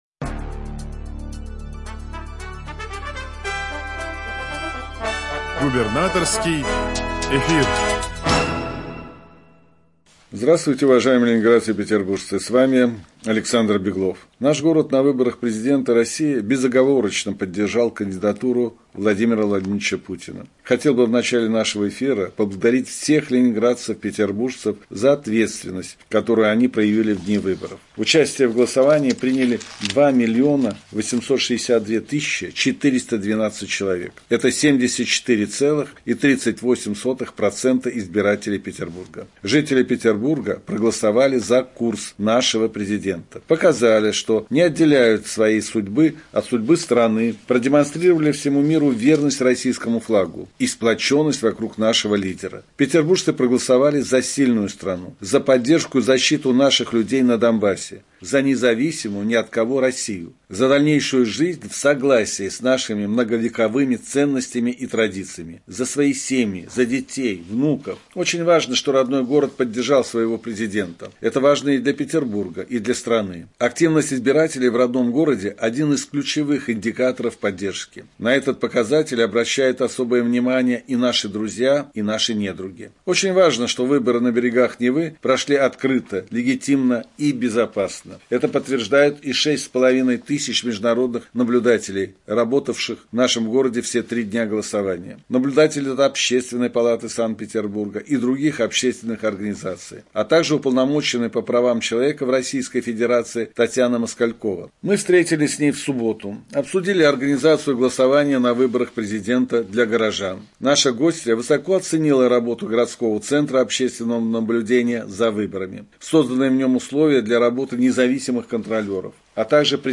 Радиообращение – 18 марта 2024 года